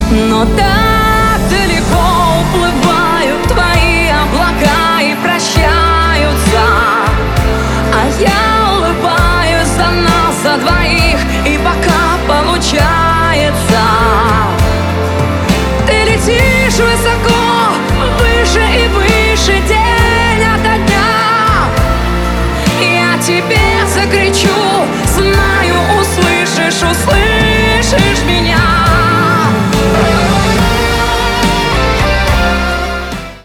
поп
грустные , гитара